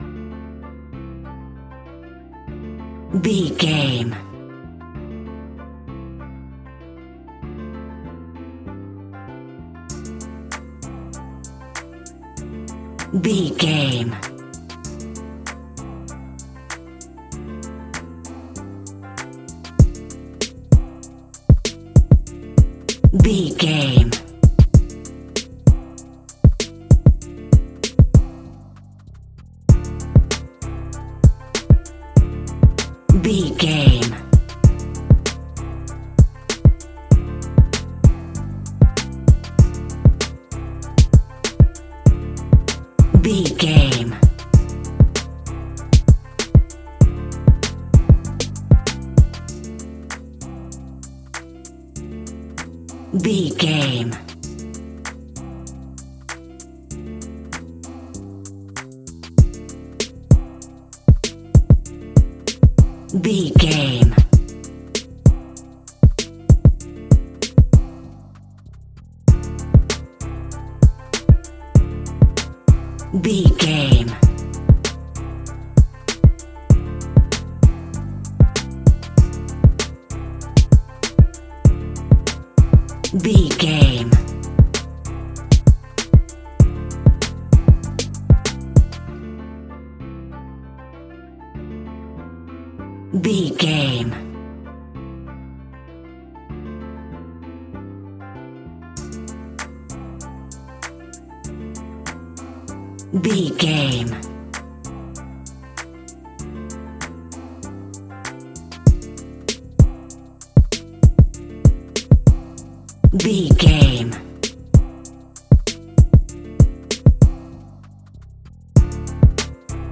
Uplifting
Aeolian/Minor
D
driving
repetitive
bouncy
energetic
drum machine
bass guitar
synthesiser
piano